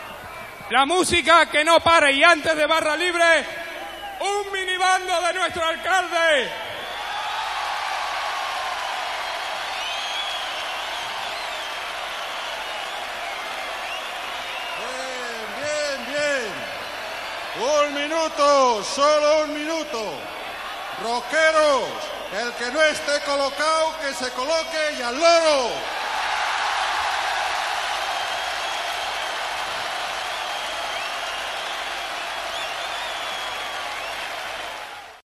Transmissió de la "Tercera Fiesta del Estudiante y la Radio", des del Palacio de Deportes de Madrid. Paraules de l'alcalde de Madrid Enrique Tierno Galván